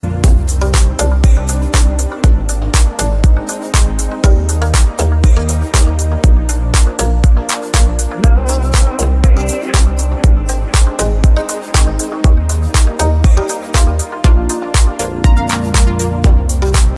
Rock Ringtones